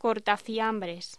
Locución: Cortafiambres
Sonidos: Hostelería